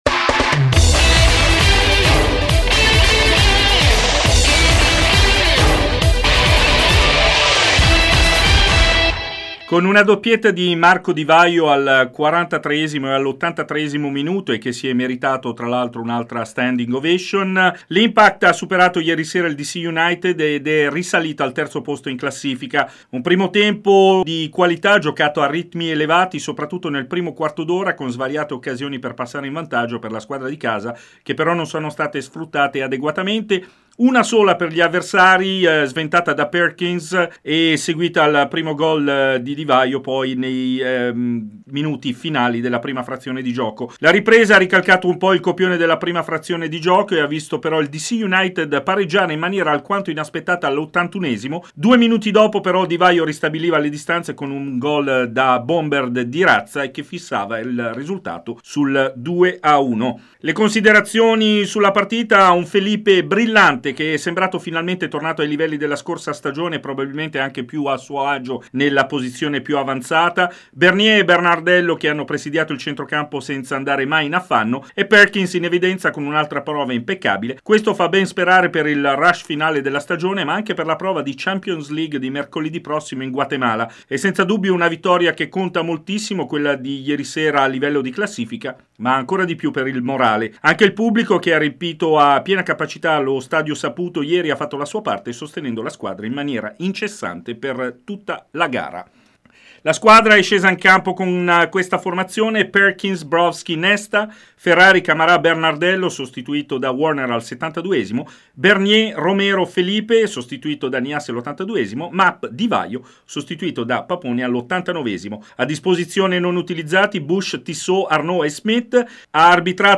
Servizio completo con le interviste